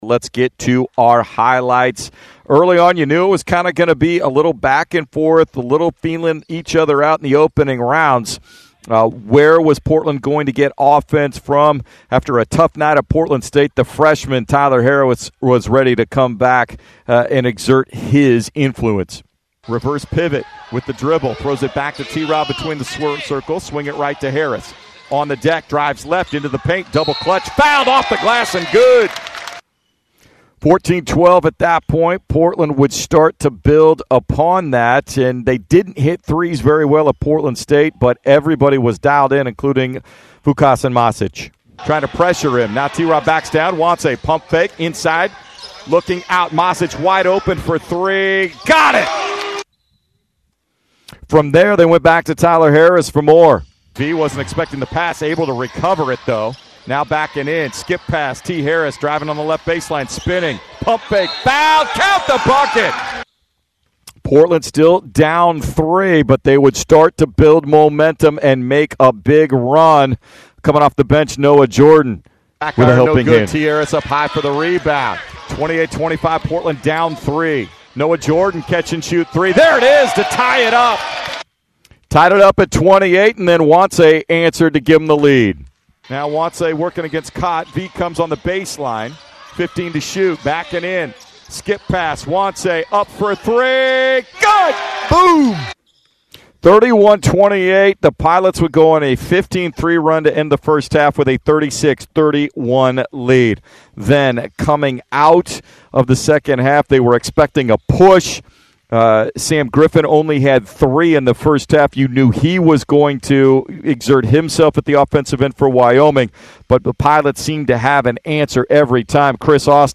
Radio Highlights vs. Wyoming